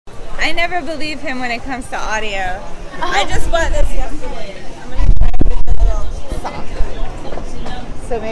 TEMP: Singlish 7
UESinglish-7.mp3